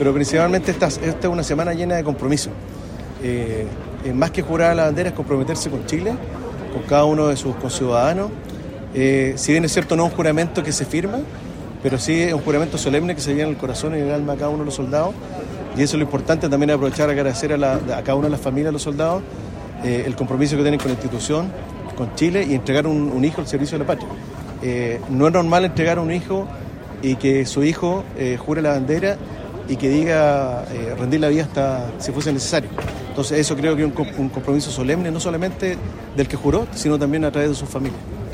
Comprometidos con la patria, 75 soldados del Destacamento de Montaña N°8 “Tucapel” realizaron el juramento a la bandera en un acto conmemorativo desarrollado en la Plaza Aníbal Pinto, rindiendo un solemne homenaje al pabellón nacional, en el marco de la conmemoración del Combate de la Concepción y el Juramento a la Bandera realizado por suboficiales, soldados de tropa profesional y soldados conscriptos del Destacamento.